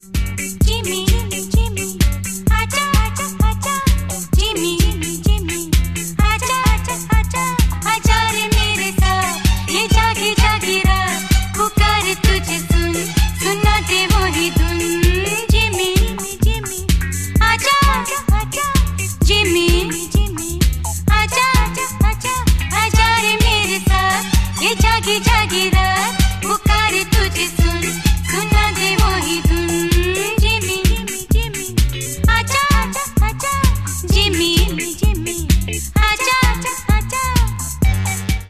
• Качество: 128, Stereo
disco
80-е
индийские